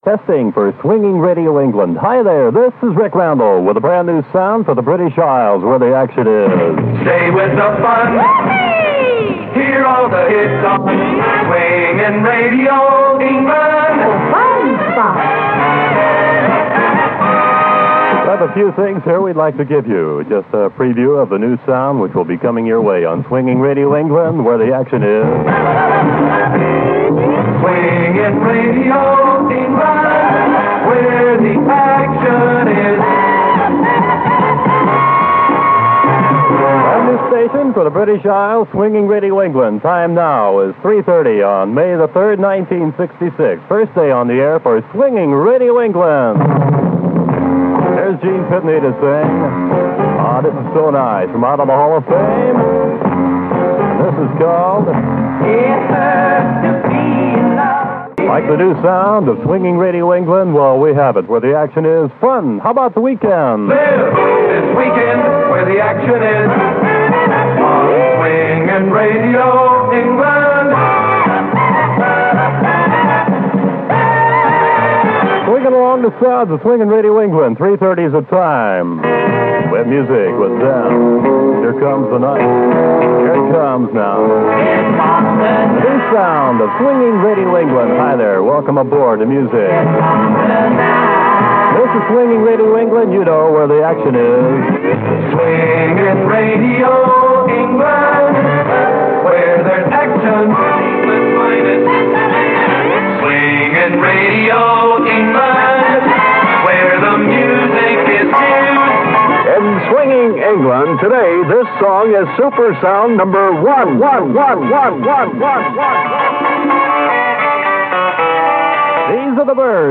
from very the first day on the air for “Swinging” Radio England